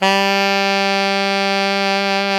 Index of /90_sSampleCDs/Roland L-CDX-03 Disk 1/SAX_Alto Tube/SAX_Alto ff Tube
SAX ALTOFF02.wav